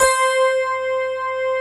Index of /90_sSampleCDs/USB Soundscan vol.09 - Keyboards Old School [AKAI] 1CD/Partition A/17-FM ELP 5